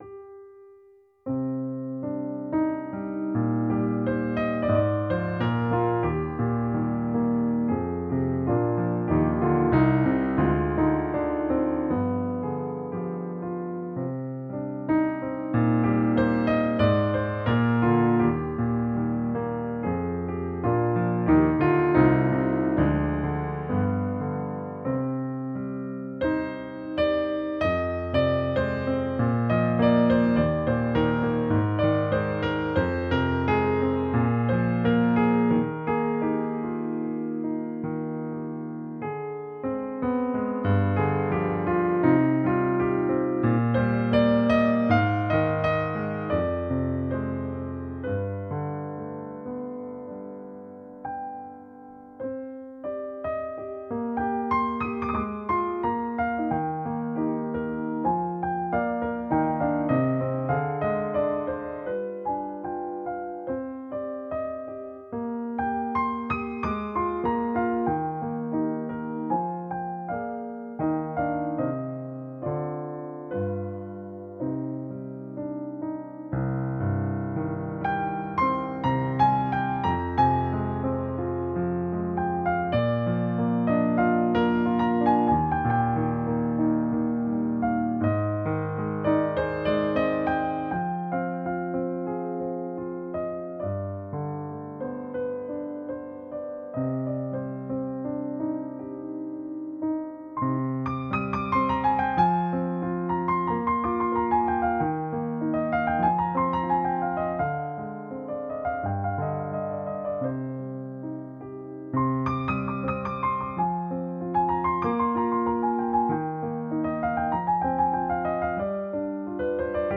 普通のピアノ曲は、ラの音を440Hzから442Hzにチューニングします。
この作品は、特別にラの音を444Hzの平均律でチューニングしてあります。
この作品には、そんな「ド」と「ラ」を多く使いました。
著作権フリーですので、BGMとして商用利用もしていただけます。